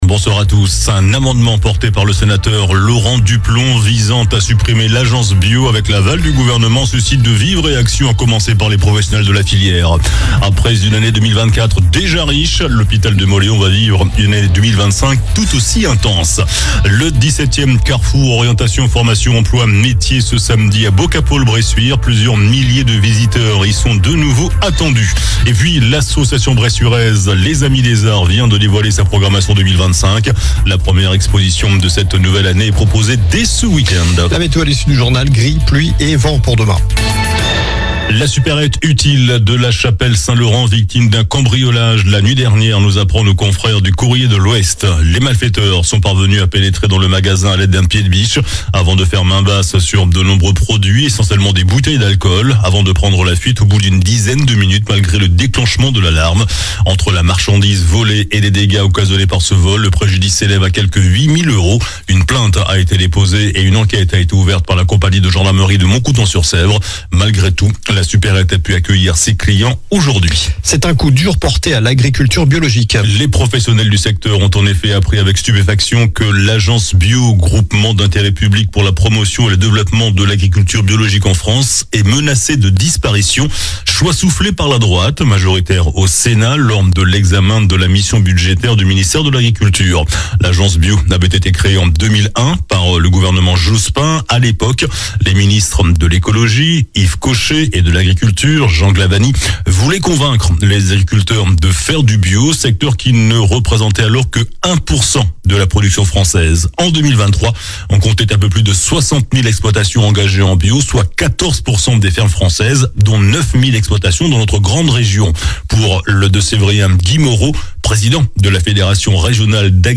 Journal du jeudi 23 janvier (soir)